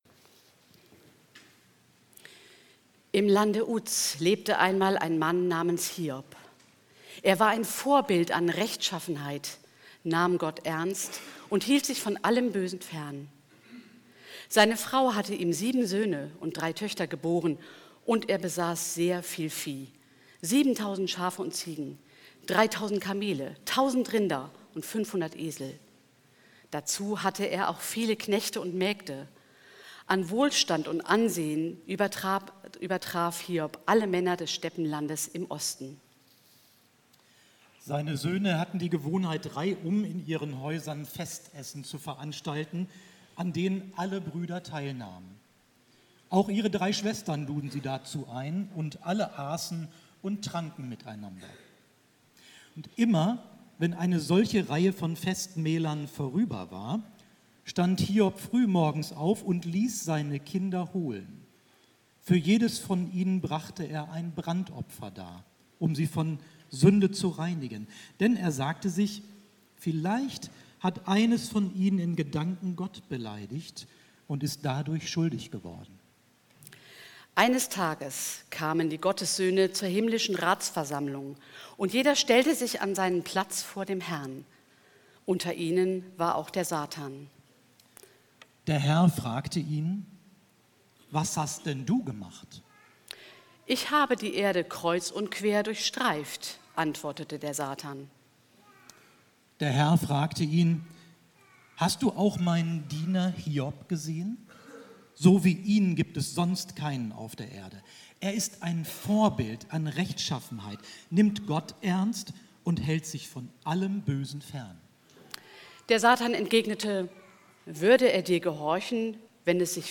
Lesung des Predigttextes:
Lesung-Hiob-1-2.mp3